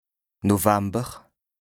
2APRESTA_OLCA_LEXIQUE_INDISPENSABLE_BAS_RHIN_75_0.mp3